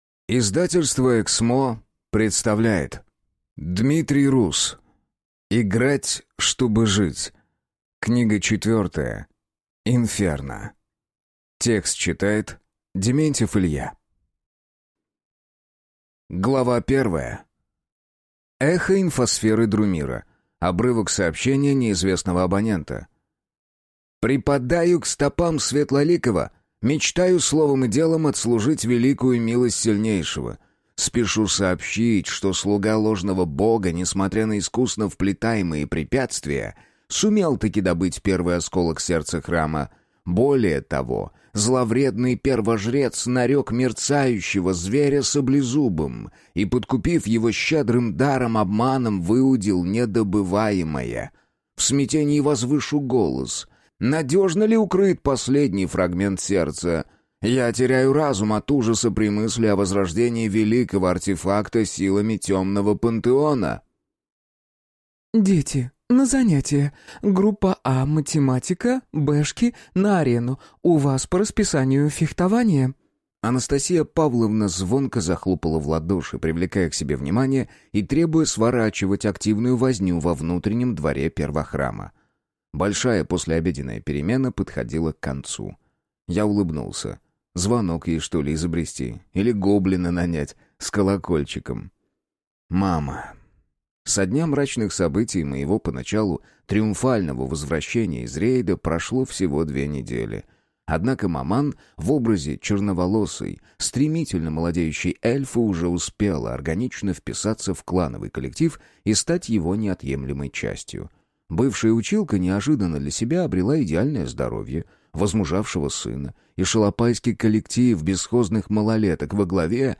Аудиокнига Играть, чтобы жить. Книга 4. Инферно | Библиотека аудиокниг